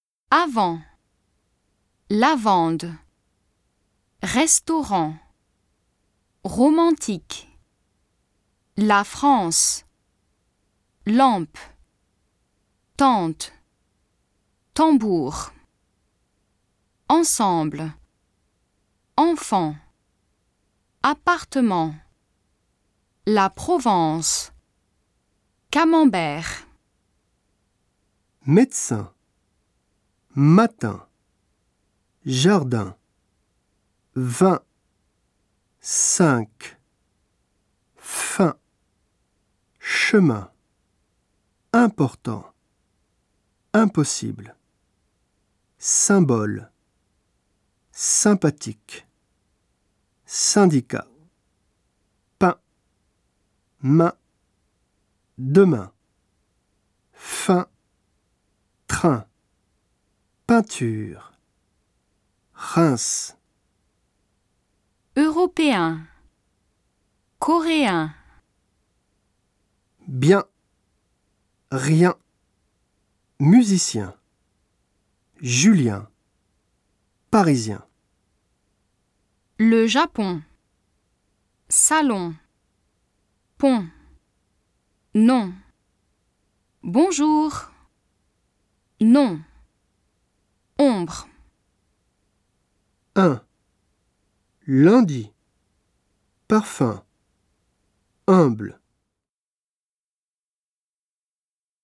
２)鼻母音
Bonjour.　nom　ombre  唇を丸める。